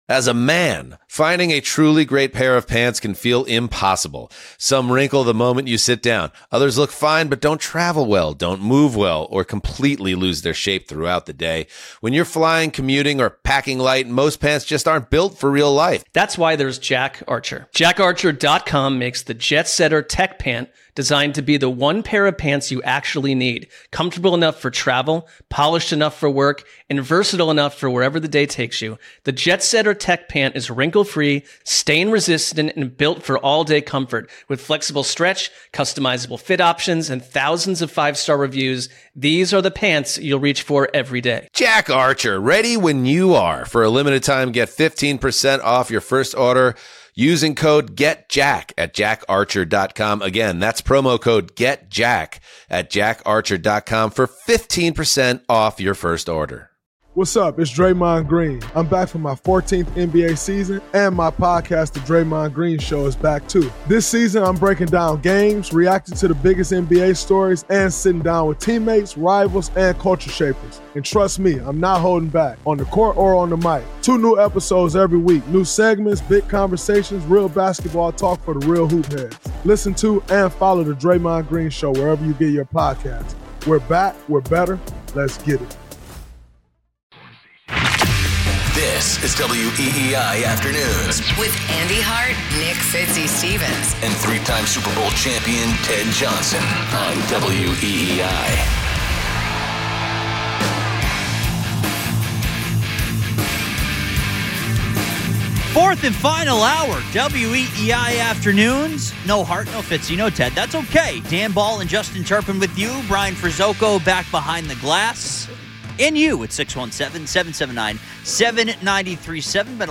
Callers join the show.…